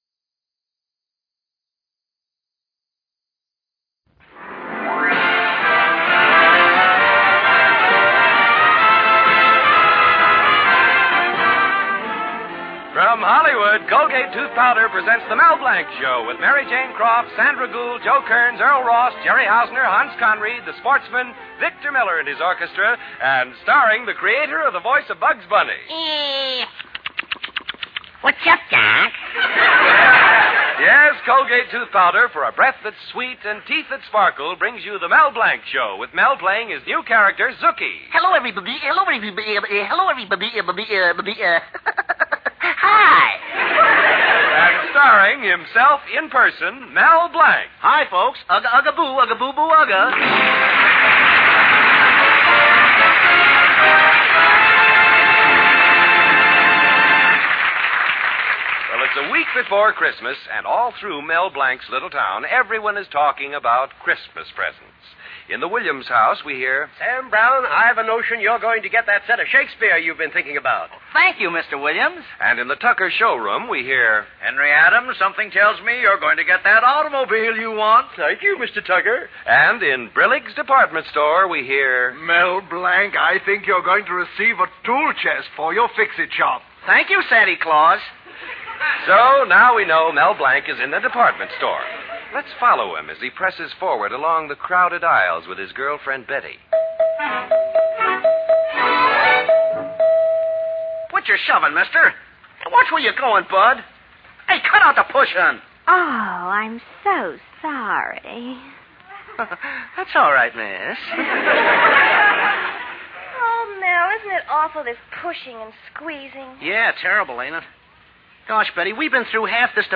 OTR Radio Christmas Shows Comedy - Drama - Variety.